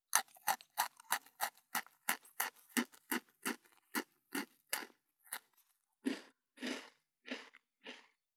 19.スナック菓子・咀嚼音【無料効果音】
ASMR